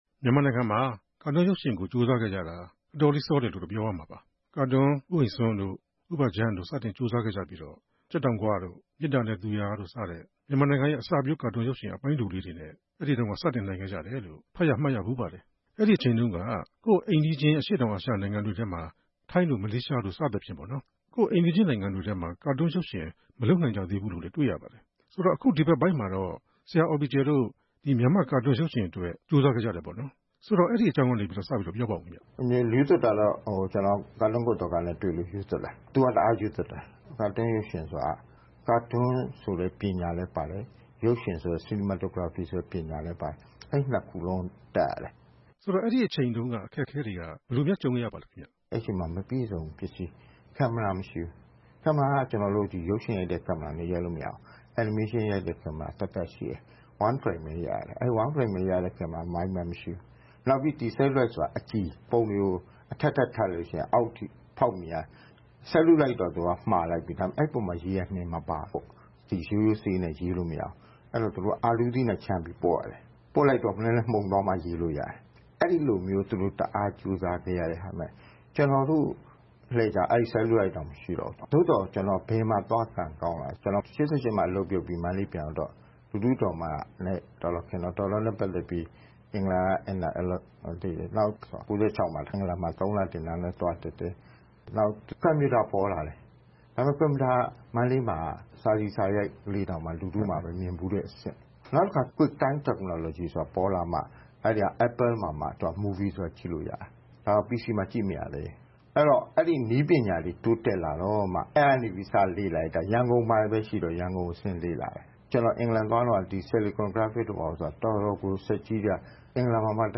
မေးမြန်းတင်ဆက်ထားပါတယ်။